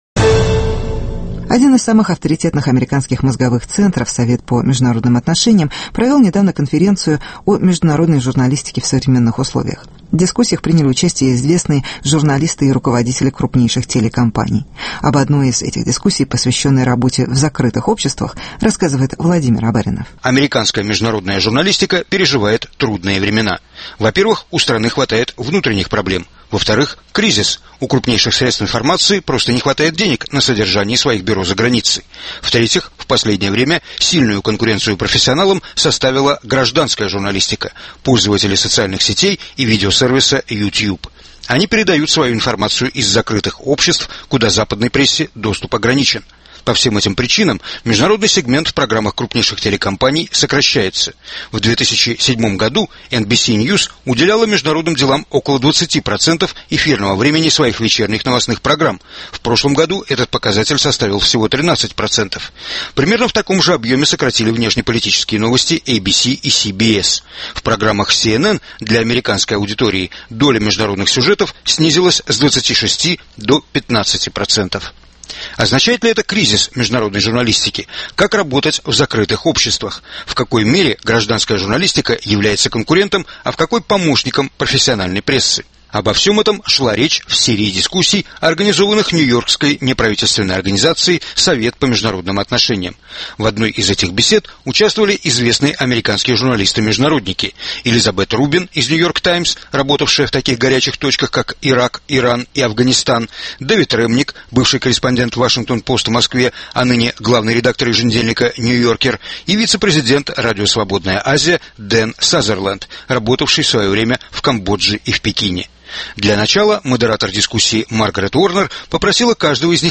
Современное телевидение: технологические вызовы и закрытые общества (дискуссия в Совете по внешней политике США)